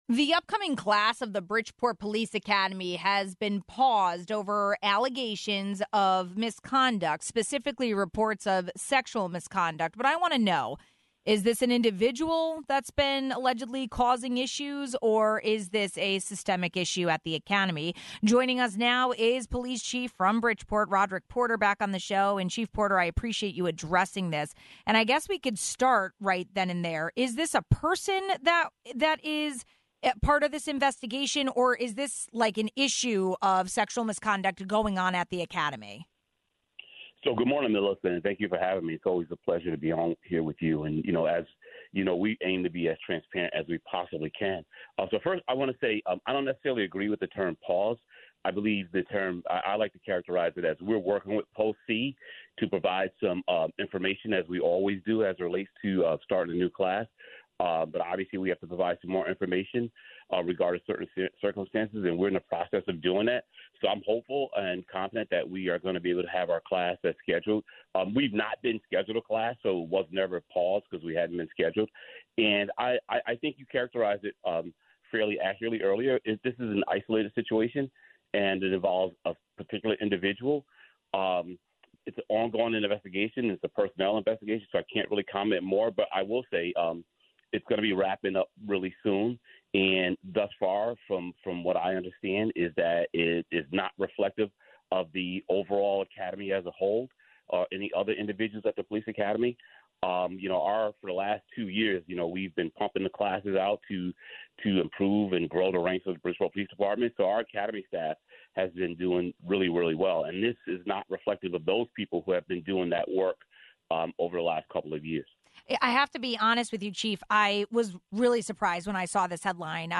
An isolated incident has reportedly impacted the upcoming class of the Bridgeport police academy. The case involves alleged sexual misconduct and for the very latest on this investigation, we spoke with Bridgeport Police Chief Roderick Porter.